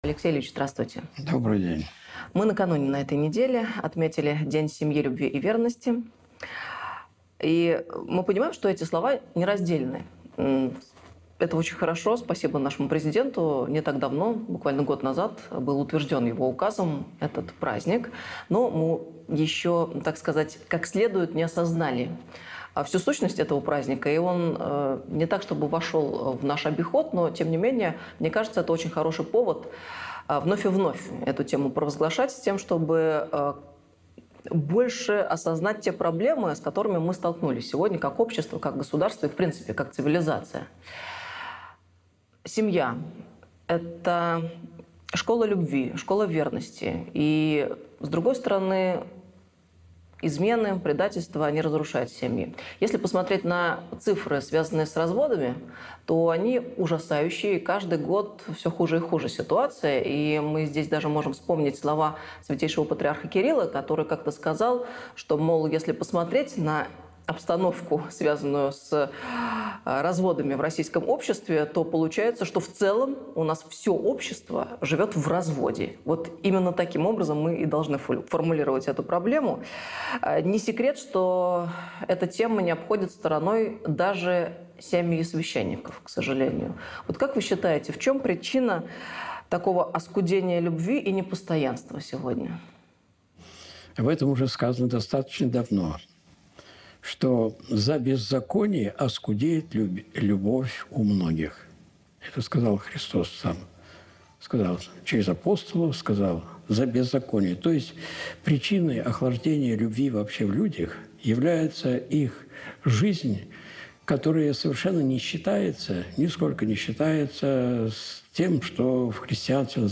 Интервью Анне Шафран (ТК "СПАС", 09.07.2023) - Осипов Алексей Ильич